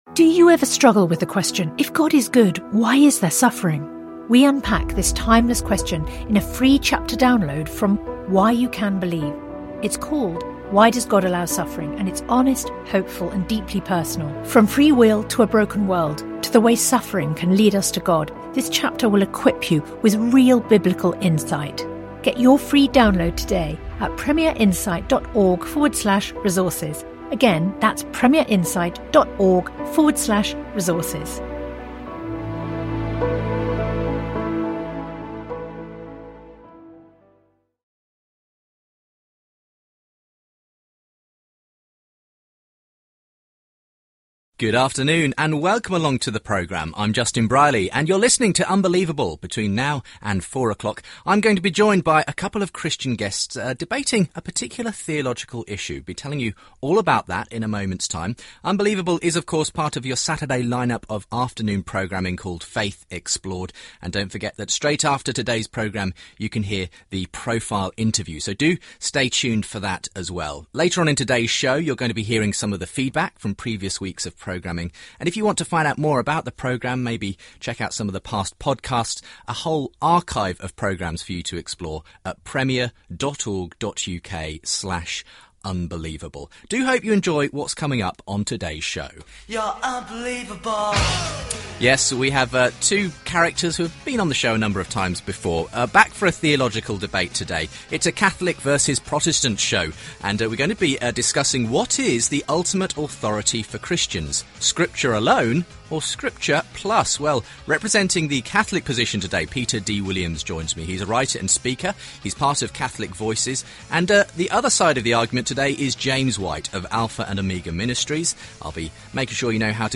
Catholic vs Protestant debate on Sola Scriptura